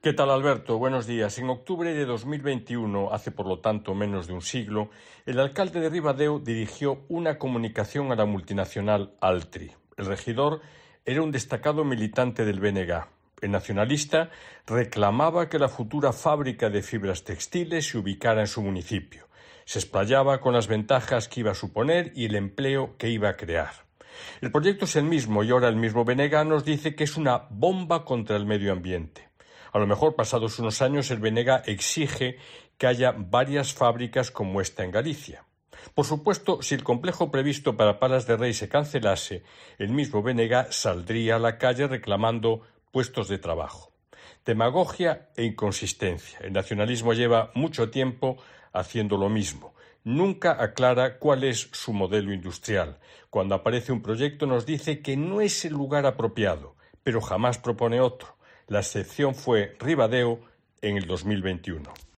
En su comentario de este viernes en Herrera en Cope Galicia